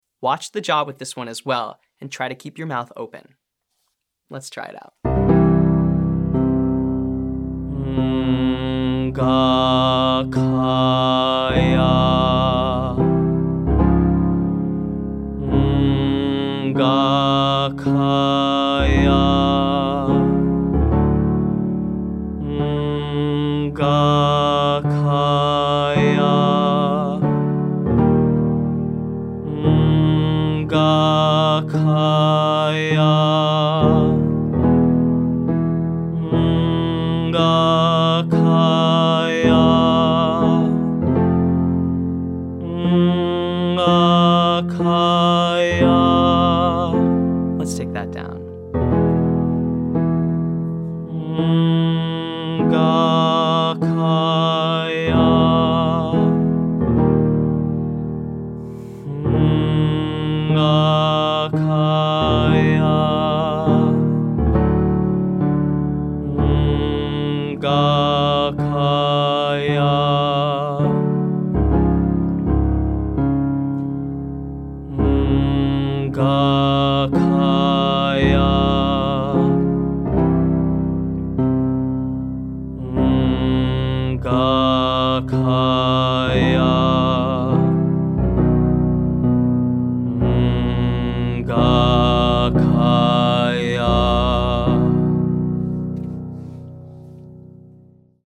• NG, Gah, Kah, Yah